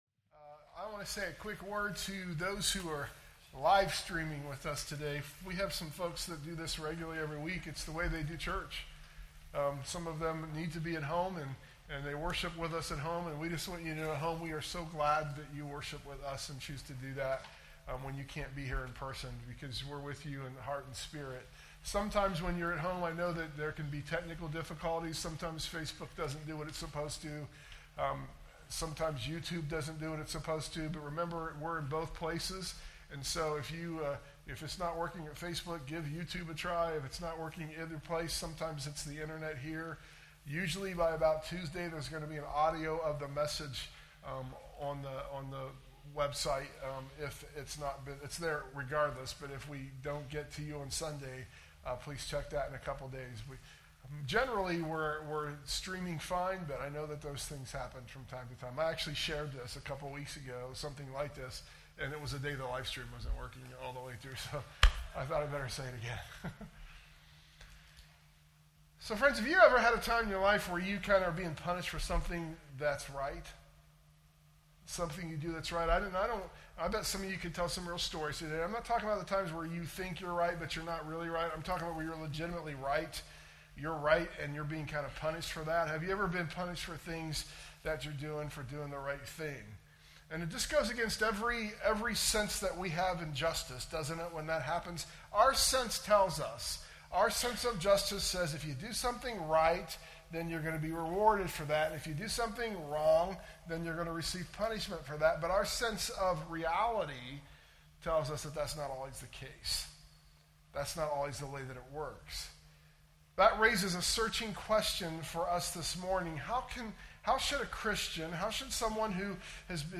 sermon_audio_mixdown_8_3_25.mp3